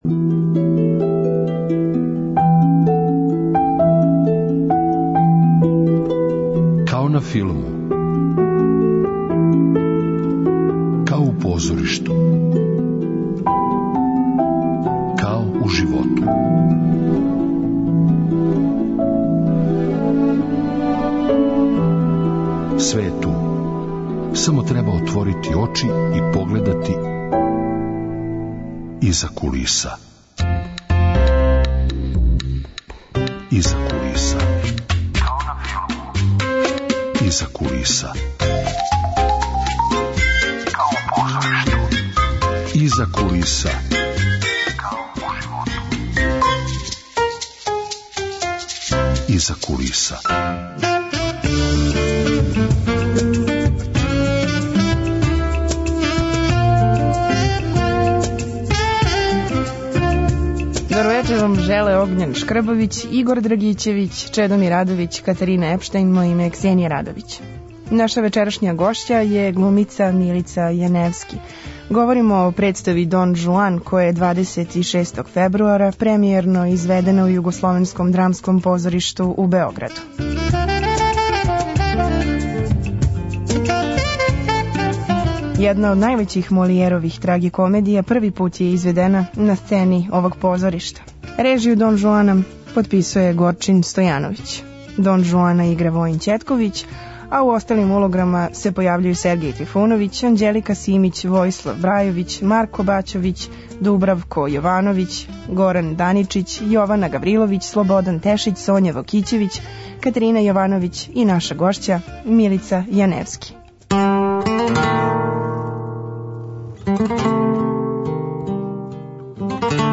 Емисија о филму и позоришту.